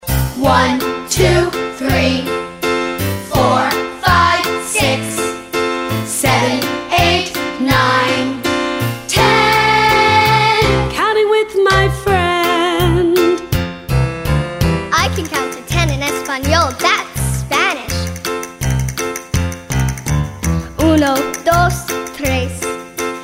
Here are two versions of this counting song.